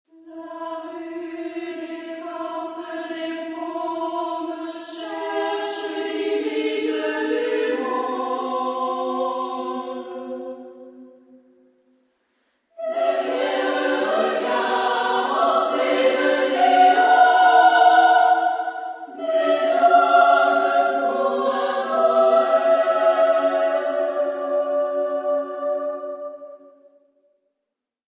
Género/Estilo/Forma: Profano ; Poema ; contemporáneo
Solistas : Soprano (2) / Alto (1)  (3 solista(s) )
Tonalidad : serial